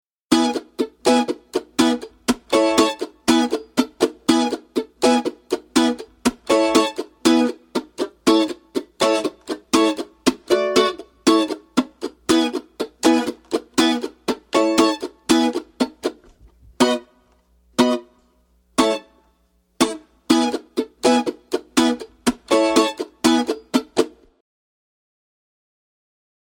Voicing: Mandolin M